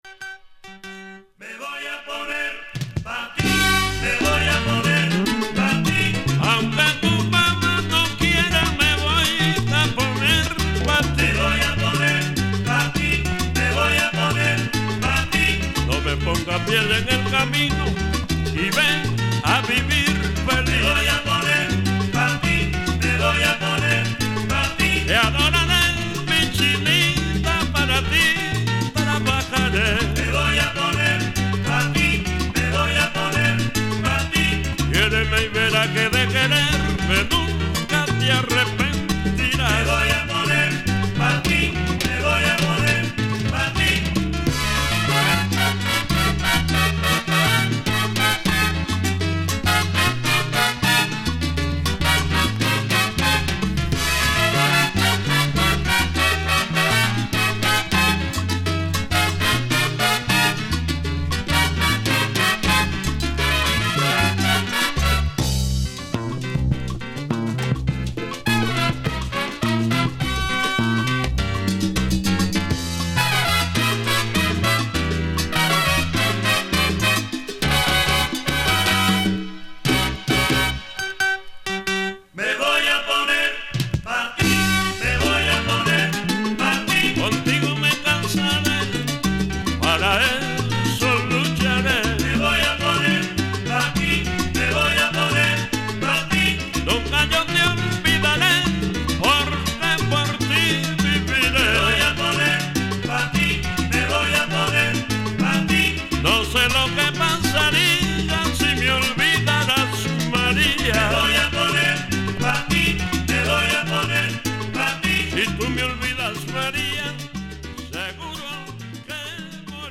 CONDITION見た目VG+,音はVG+(+) / VG+ (リングウェアー , シミ) (VINYL/JACKET)